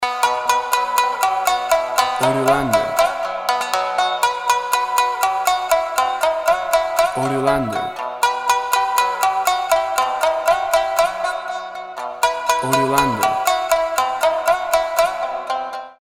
A trip to the middle east with the sound of the arab flute.
Tempo (BPM) 120